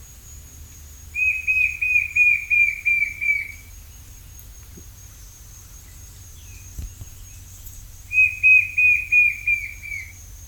White-shouldered Fire-eye (Pyriglena leucoptera)
Life Stage: Adult
Location or protected area: Reserva Privada y Ecolodge Surucuá
Condition: Wild
Certainty: Recorded vocal